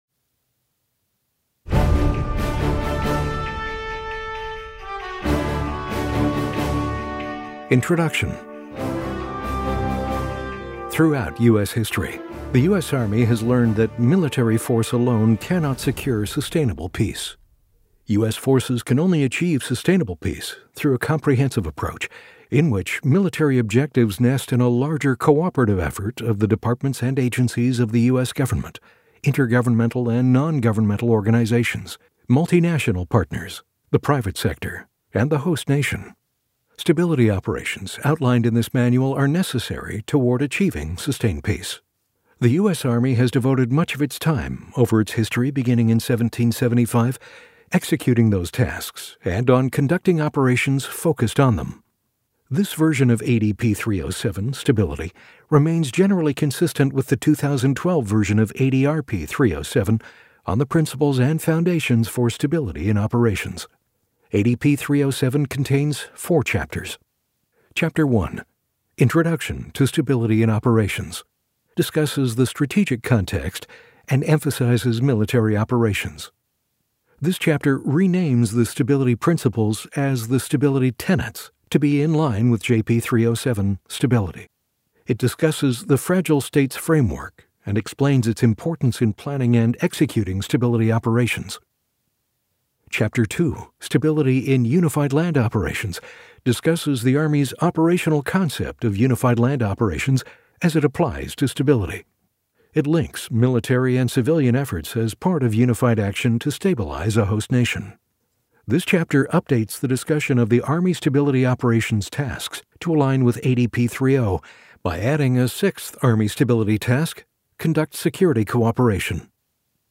Army Doctrine Audiobook Download Page for Introduction ADP 3-07 Stability.